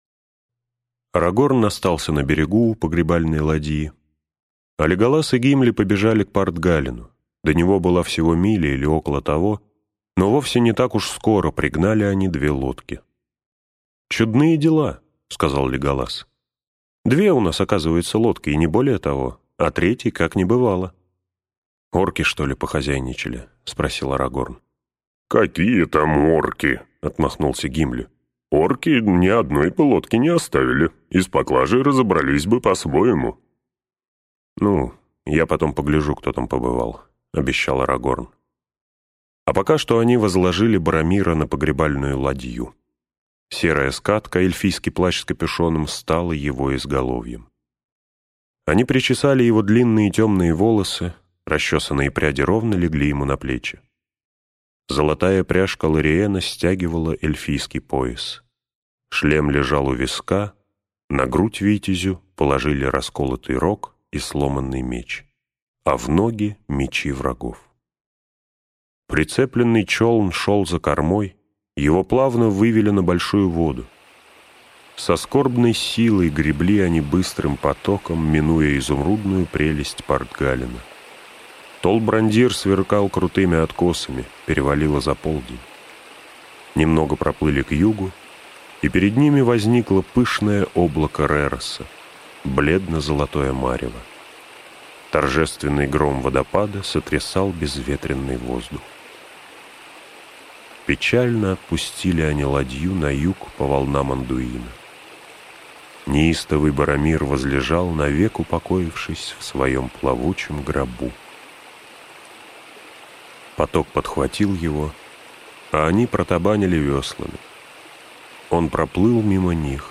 Аудиокнига Две твердыни - купить, скачать и слушать онлайн | КнигоПоиск